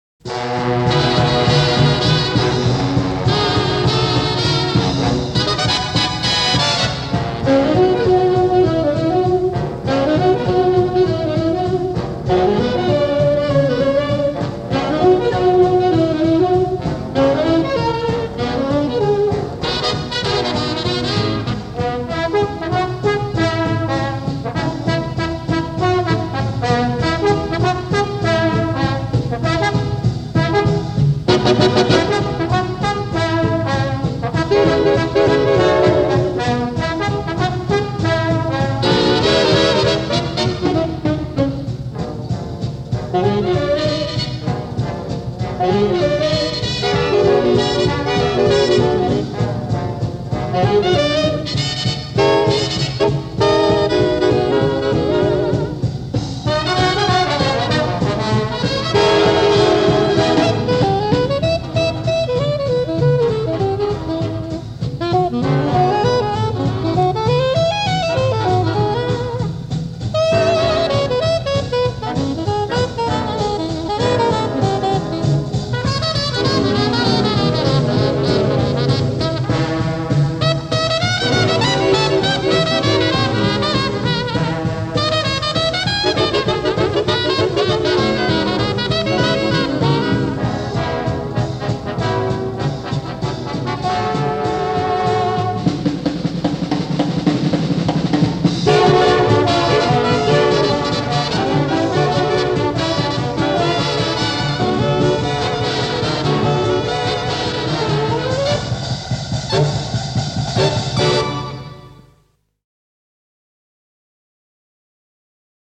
Jazz in Switzerland (Vol. 1)